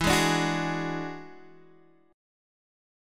Listen to E9 strummed